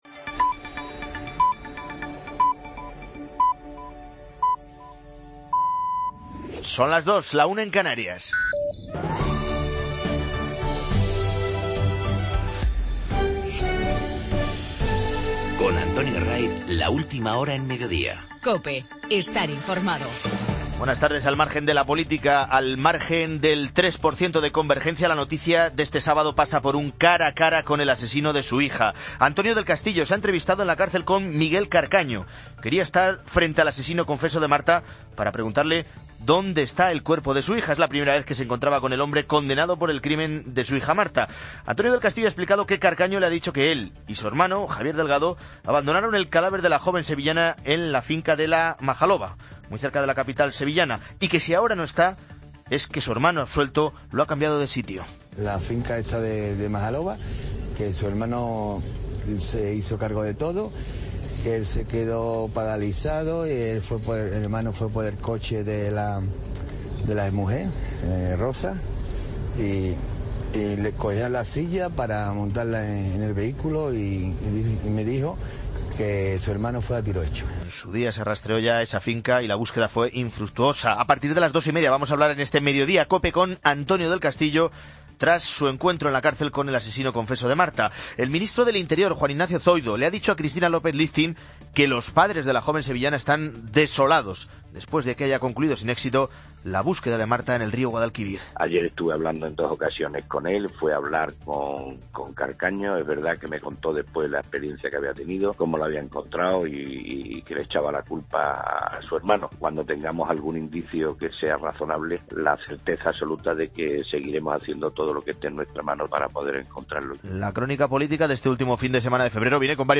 el equipo de informativos de fin de semana